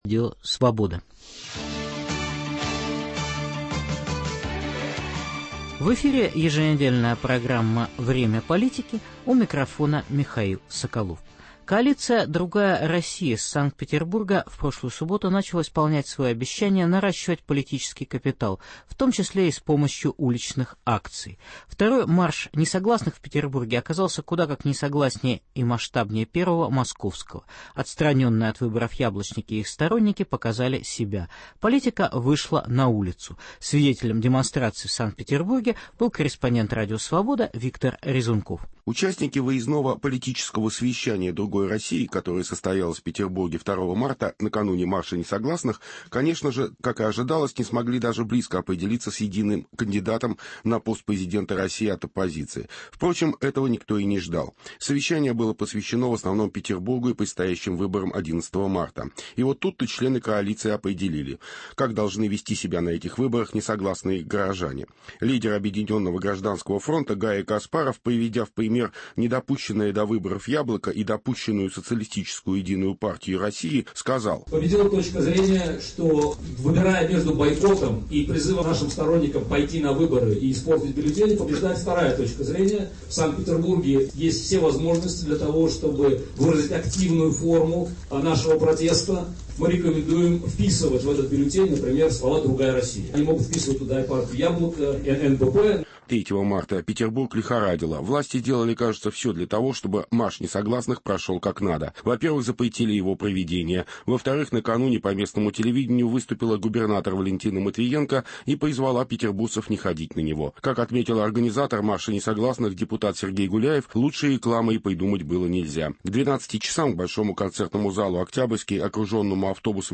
Россия перед региональными выборами 11 марта. Репортажи из Республики Коми, Ставропольского края, Орловской, Псковской, Томской, Самарской, Московской областей.